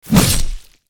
bt_cha_boom1.mp3